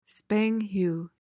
PRONUNCIATION: (SPANG-hyoo) MEANING: verb tr.: To throw violently into the air.